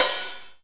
OPEN HAT.wav